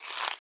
Crunch Bite Item (3).wav